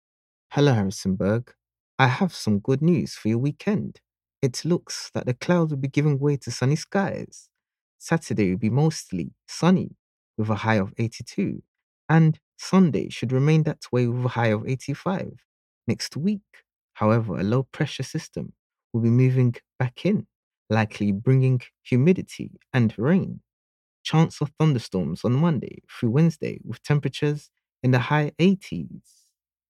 Canon Voiceover Commercials Voiced by Top Talent
I've a versatile voice. Can I do dynamic teenagers voices or voices elegants for corporates products. All records with the profesional quality of my studio....